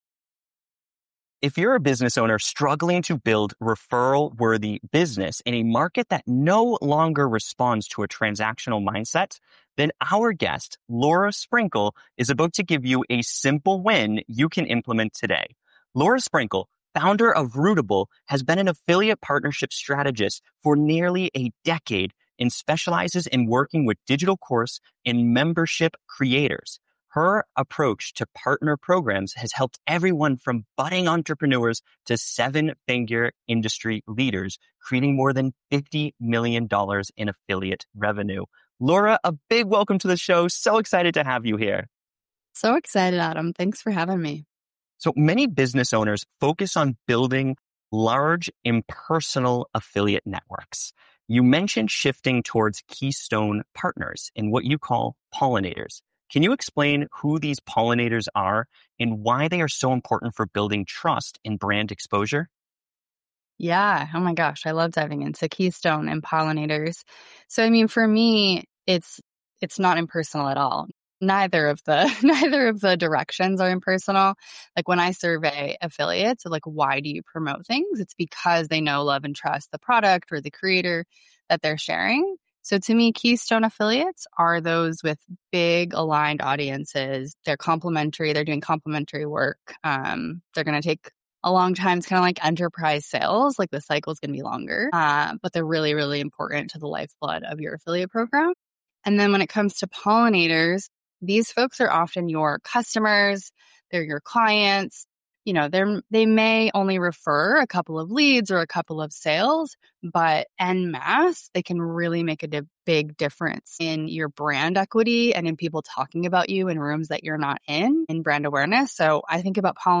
powerful conversation